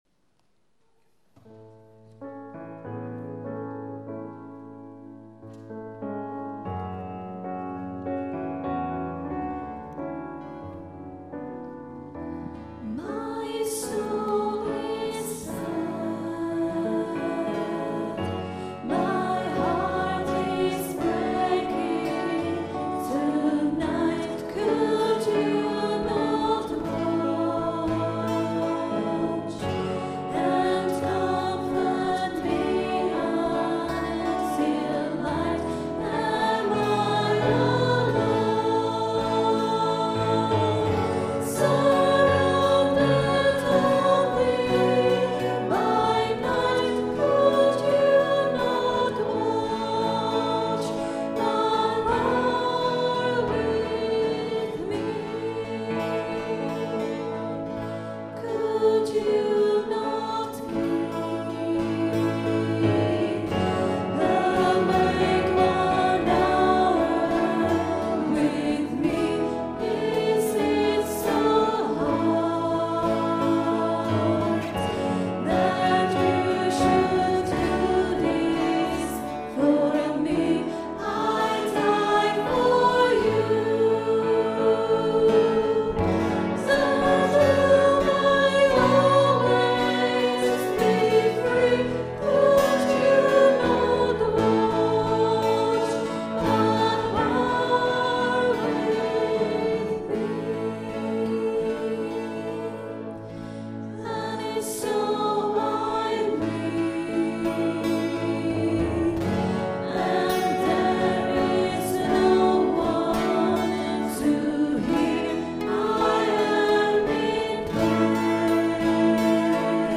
Recorded on a Zoom H4 digital stereo recorder at Maundy Thursday Mass 21st April 2011.